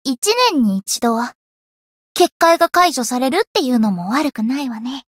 灵魂潮汐-神纳木弁天-七夕（摸头语音）.ogg